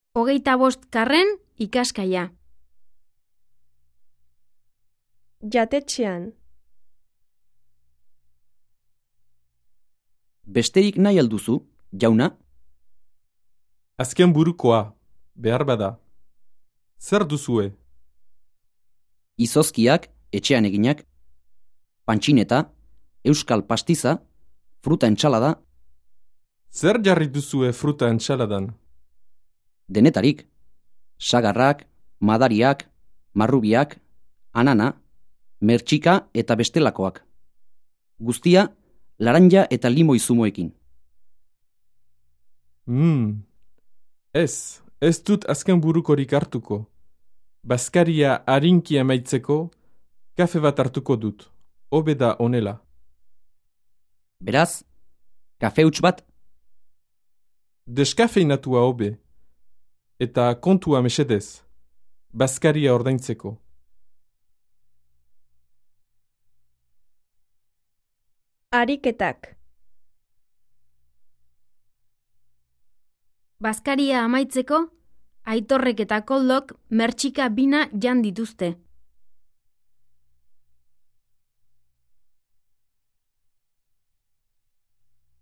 Диалог
1 besterik nay aldussu jauna 4 issosskiak echean eginak... 6 ...ess esstut asskenburukorik artuko...